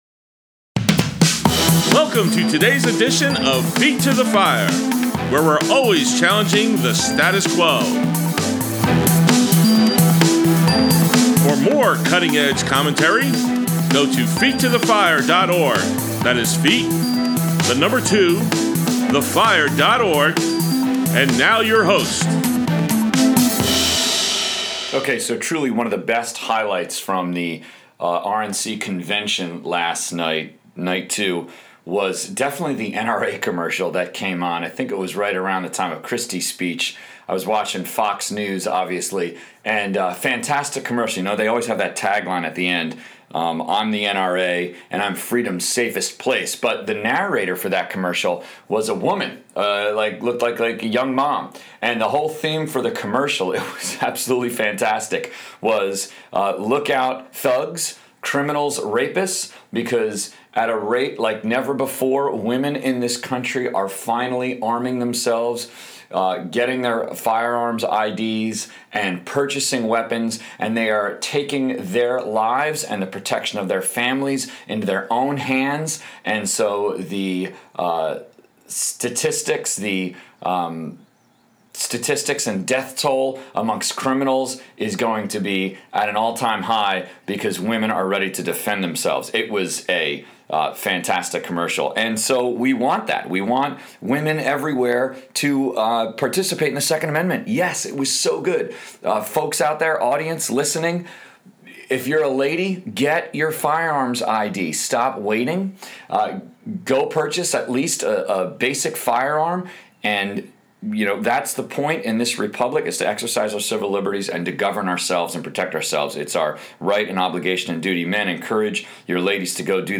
Highlights from the RNC Convention in Ohio | Feet to the Fire Politics: Conservative Talk Show